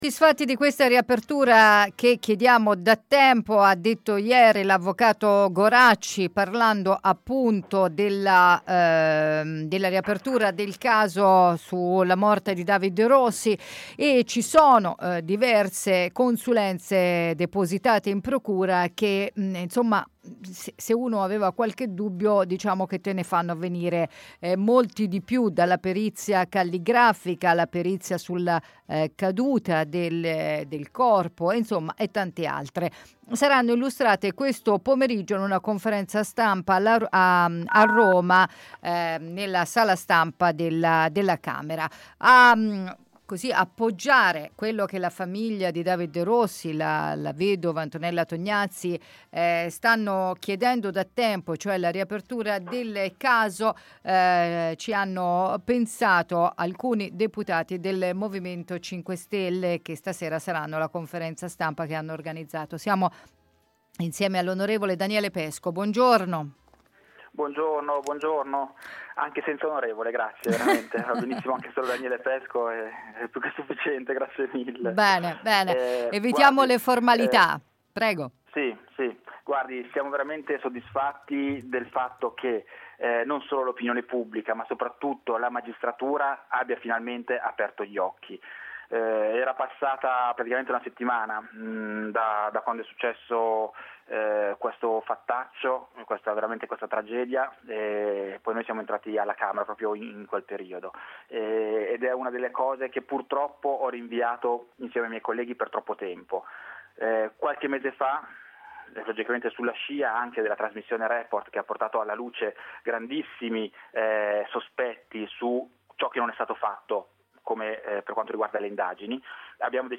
Conferenza stampa oggi a Montecitorio per la riapertura del caso David Rossi: il deputato del M5S Daniele Pesco.